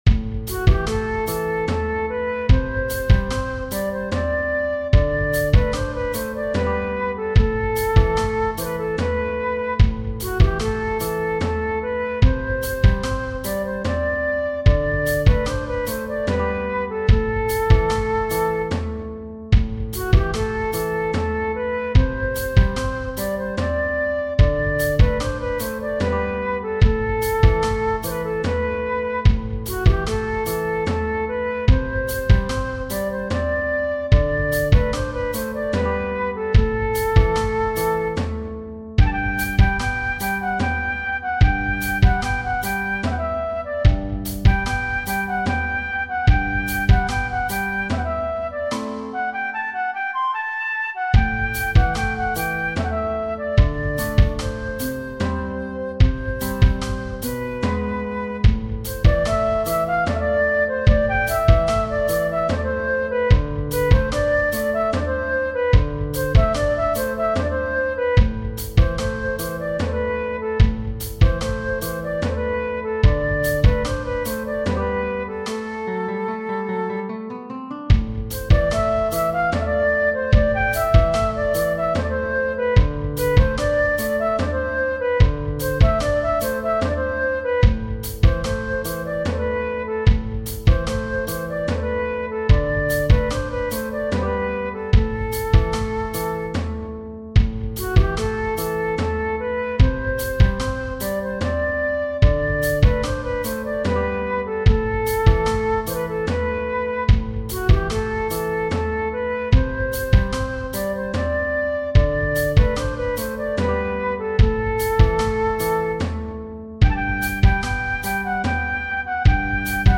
Tradizionale Genere: Folk "Erzrumi Shoror". o "Erzeroumi Shoror", è una melodia tradizionale armena utilizzata per una danza.